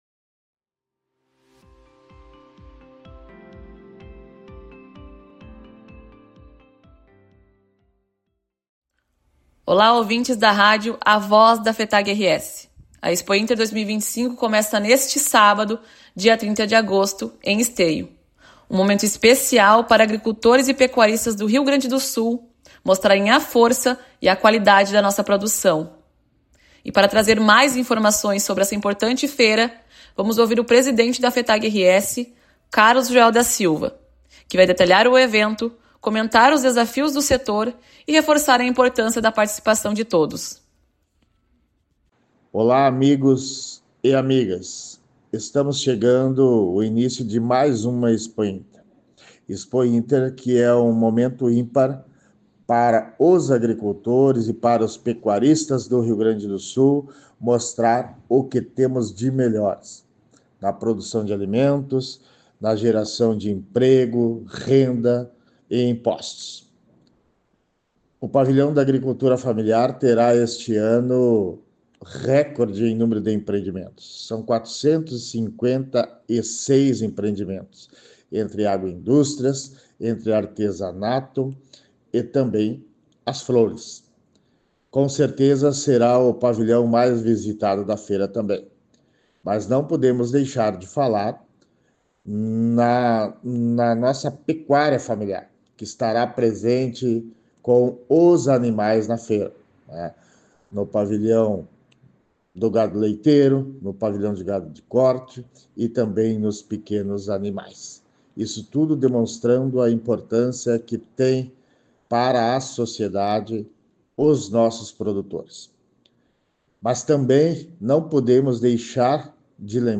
Programa de Rádio A Voz da FETAG-RS
A série especial A Voz da Fetag-RS – Expointer 2025 traz informações, entrevistas e destaques sobre uma das maiores feiras da América Latina, que acontece de 30 de agosto a 7 de setembro, no Parque Assis Brasil, em Esteio.